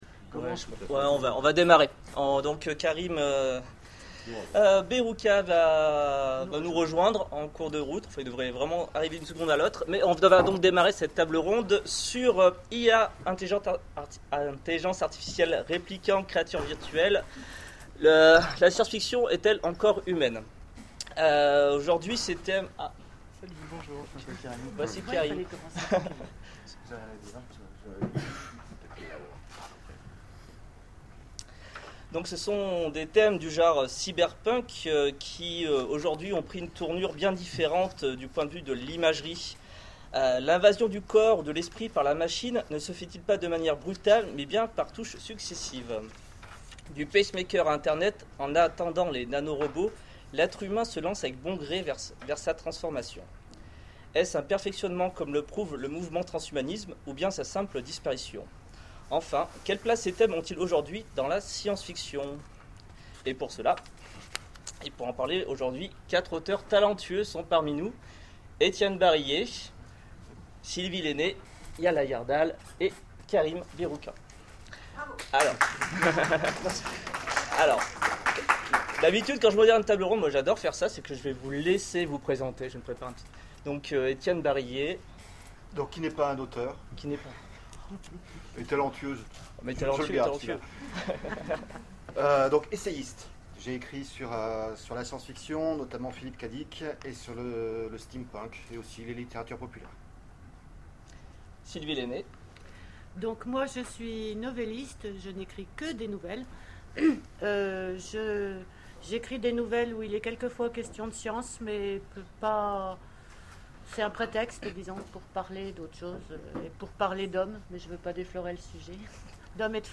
Oniriques 2013 : Conférence I.A., Réplicants, Créatures virtuelles